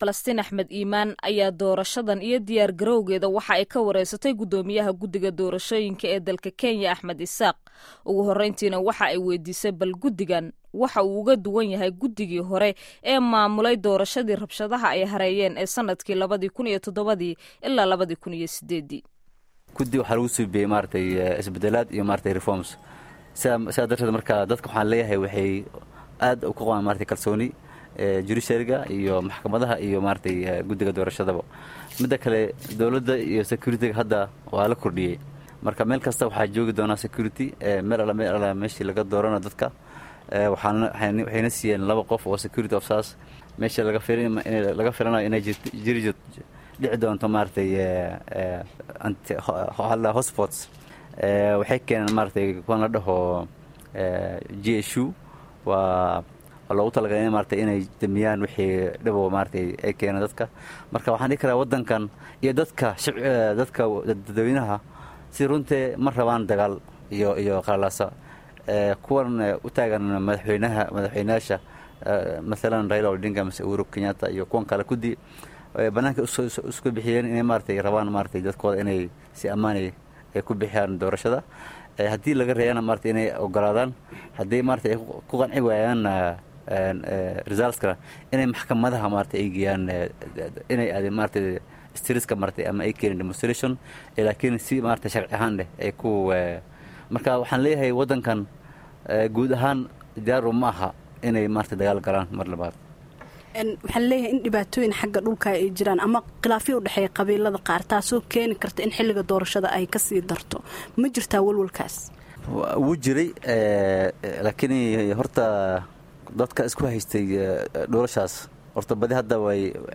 Wareysiga Guddoomiyaha Guddiga Doorashada Kenya